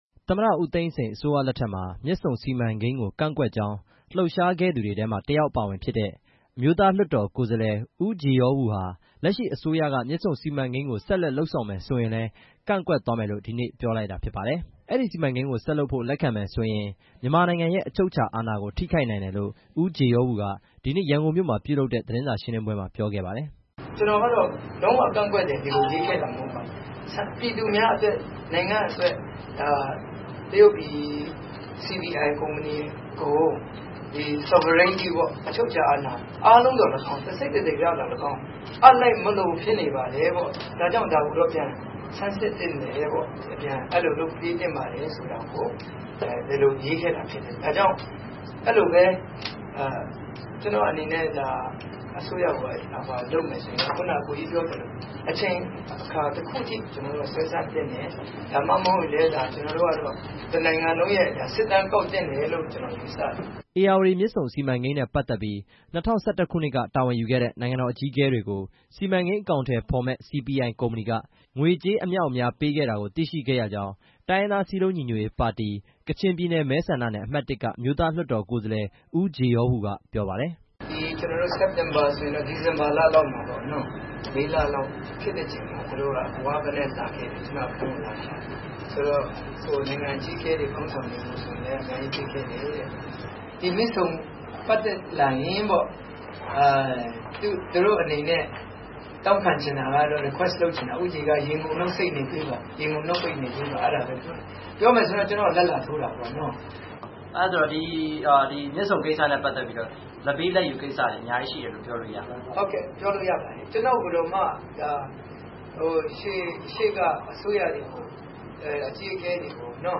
ရန်ကုန်တိုင်းဒေသကြီး ရန်ကင်းမြို့ Real Link ဟိုတယ်မှာ ဦးဂျေယောဝူက မြစ်ဆုံစီမံကိန်းကို ကန့်ကွက်ကြောင်း သတင်းစာရှင်းလင်းပွဲမှာ ပြောကြားလိုက်တာဖြစ်ပါတယ်။